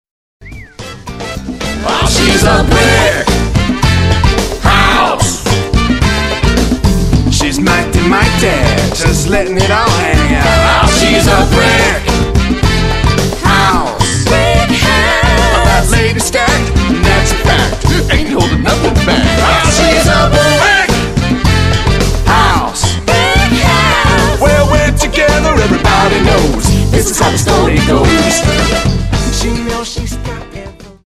backups only